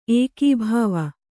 ♪ ēkībhāva